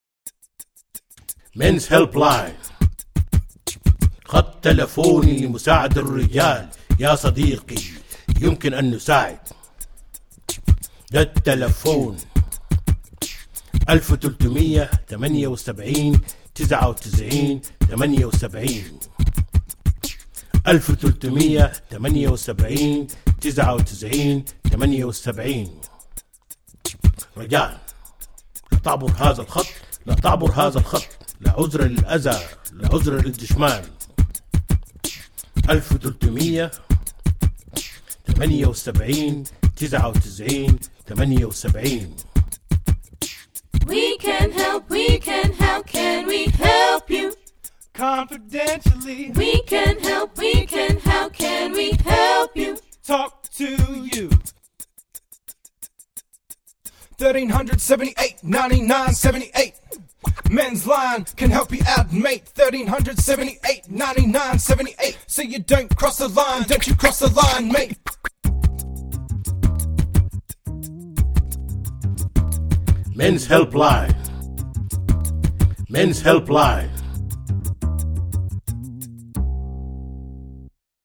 Family and Domestic VIolence Helpline raps: